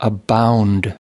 واژه ششم) abound /ǝ bound´/ معنی: پربار بودن، وافر بودن، فراوان بودن، وفور داشتن، سرشار بودن مثال:
Abound.mp3